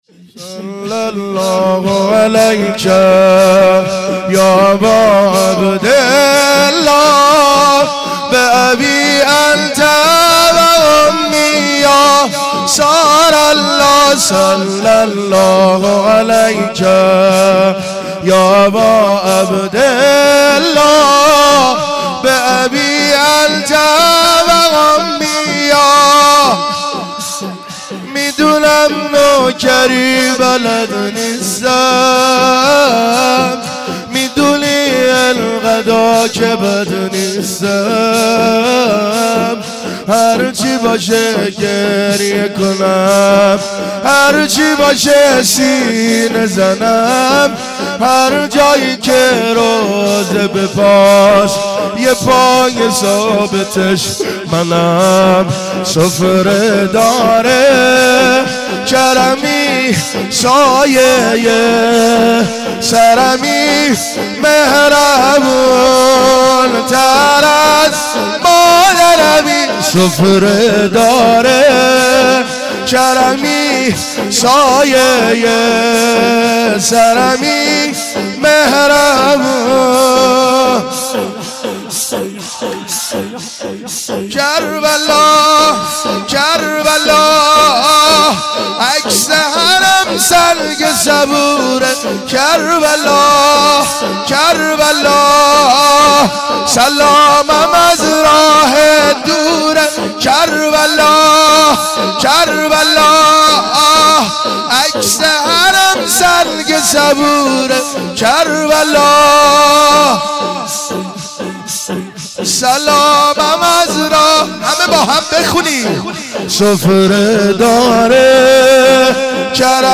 عقیق: مراسم عزاداری دهه دوم صفر در هیئت طفلان مسلم مرکز آموزش قرآن و معارف اسلامی برگزار شد.